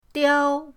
diao1.mp3